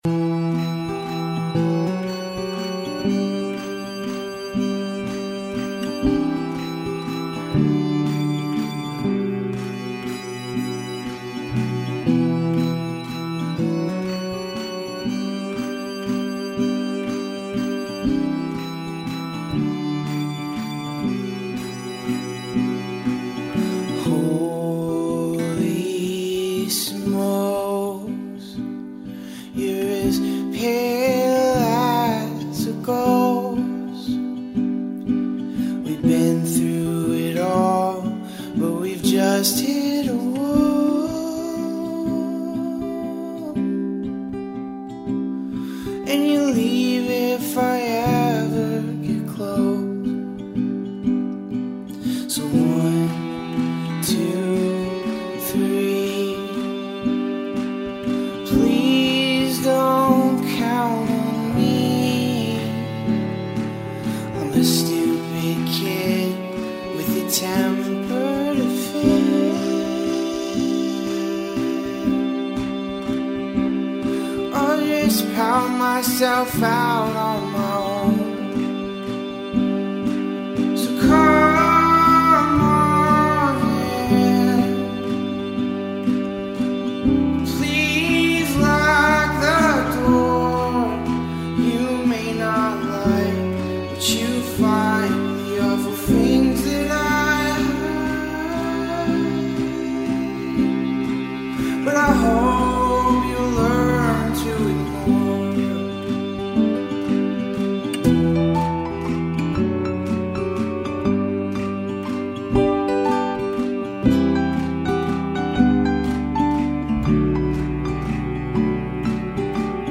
indie-folk rock band
This beautifully sung single